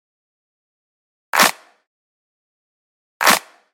Dirty Dutch Big Room Full Drums
描述：a nice fully packed drum/percussion loop
标签： 128 bpm House Loops Drum Loops 646.17 KB wav Key : F
声道立体声